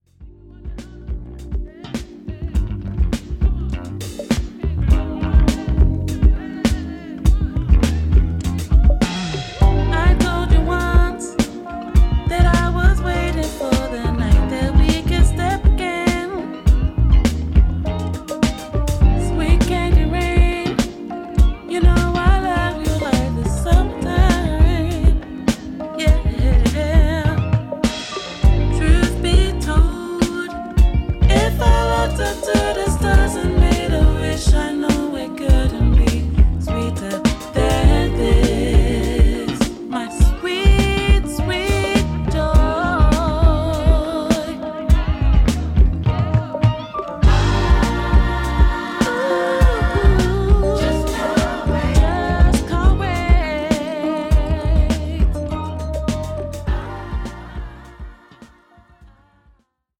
JAZZ / JAZZ FUNK / FUSION